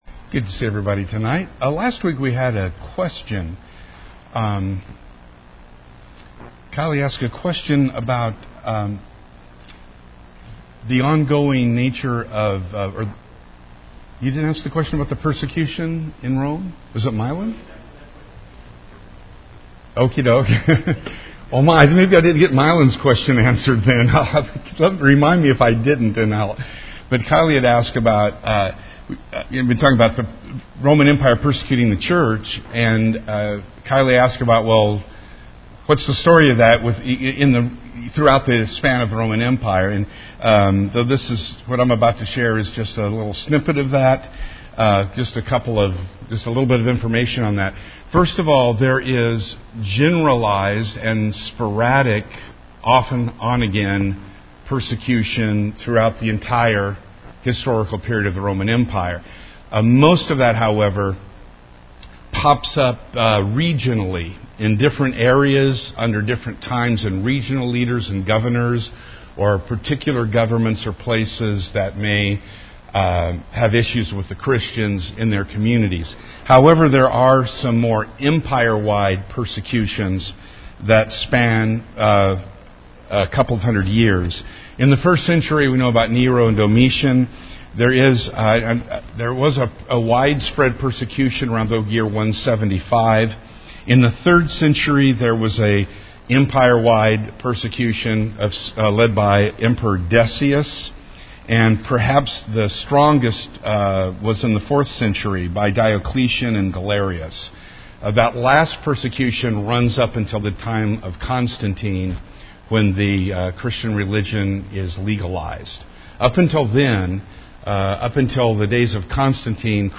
This is the twenty-ninth part of our Wednesday night class on Revelation.